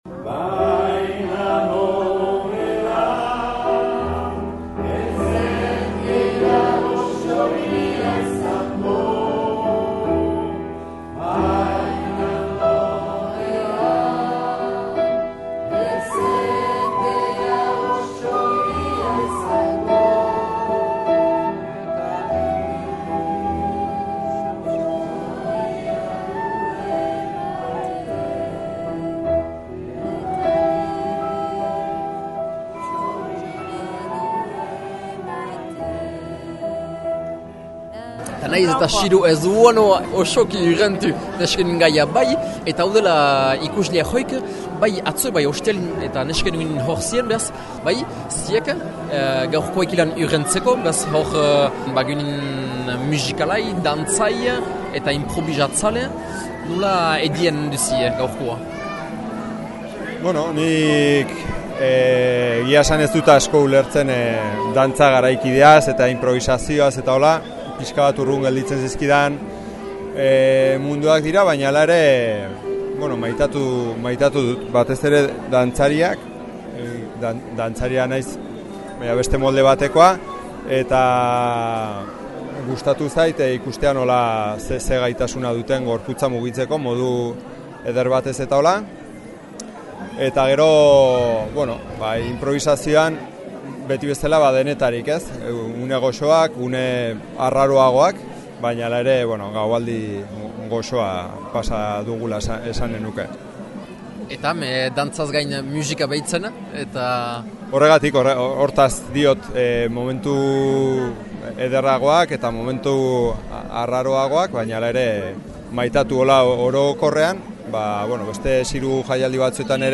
XIRU 2019 erreportai.mp3